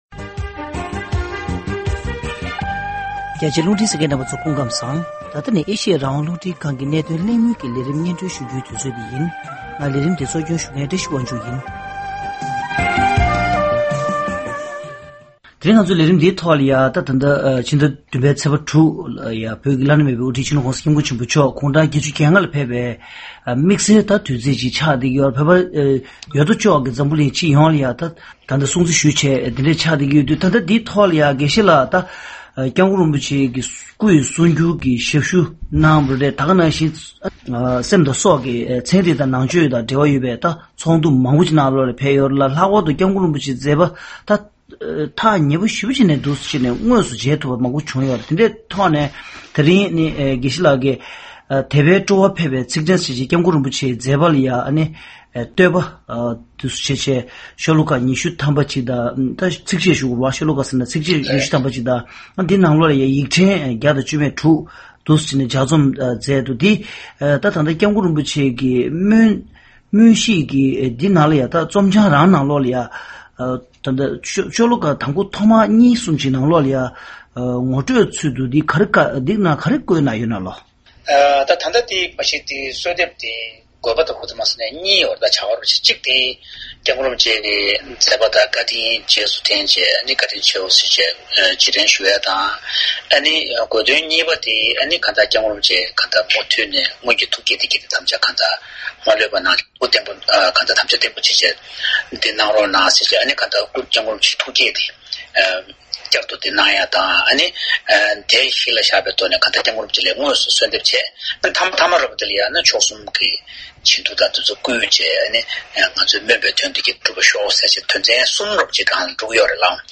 ༄༅།།ཐེངས་འདིའི་གནད་དོན་གླེང་མོལ་གྱི་ལས་རིམ་ནང་།